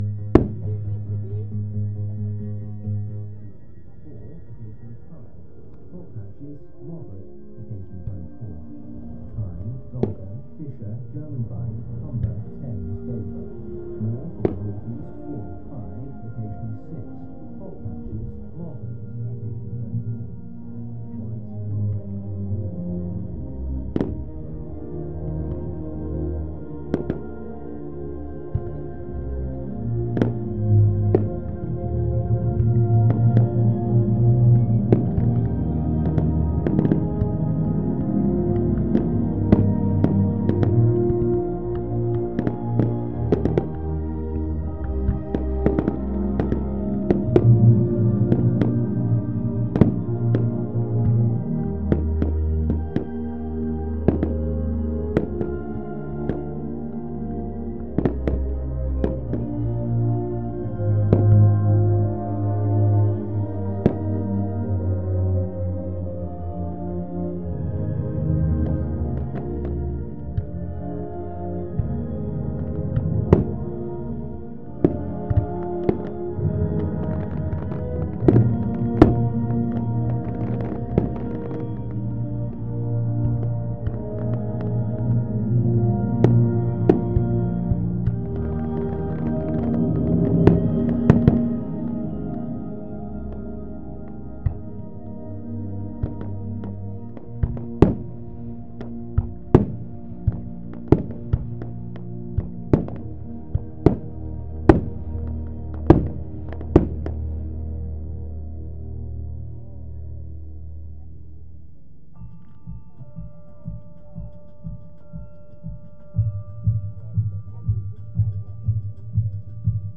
Firework Championship 2 Team 2. Middle of display